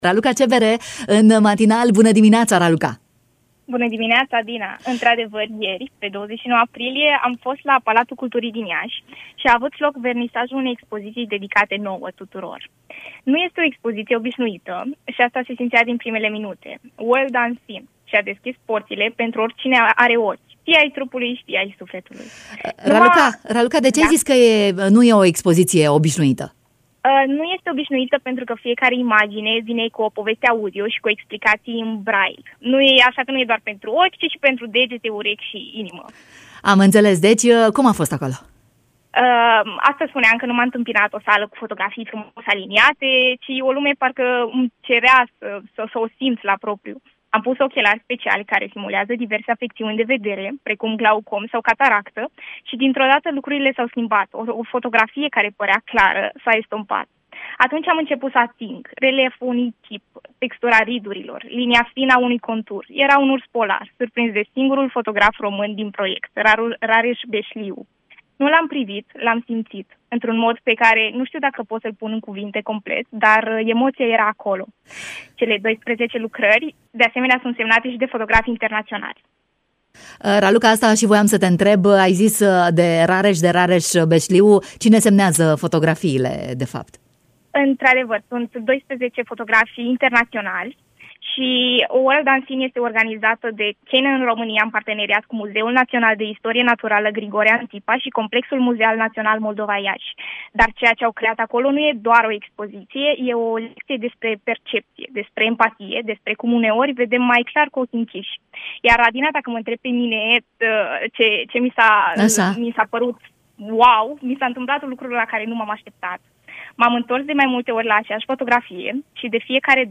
s-a auzit astăzi în matinalul Radio România Iași